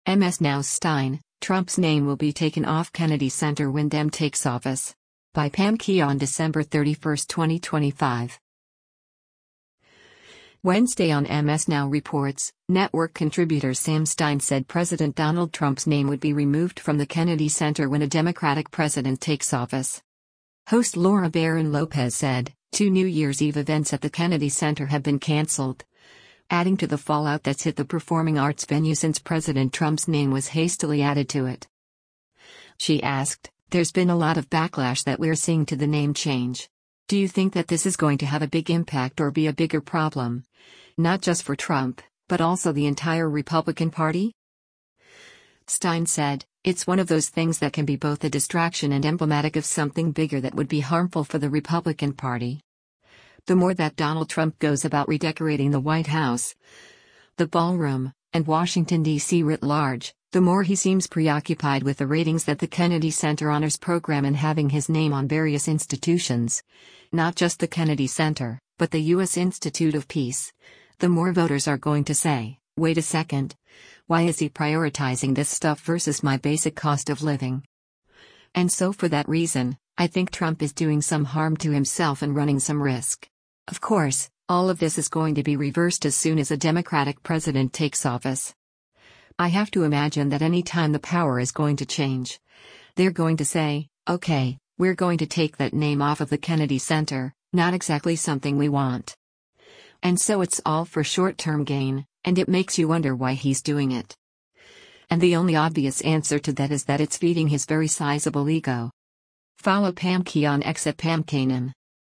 Wednesday on “MS NOW Reports,” network contributor Sam Stein said President Donald Trump’s name would be removed from the Kennedy Center when a Democratic president takes office.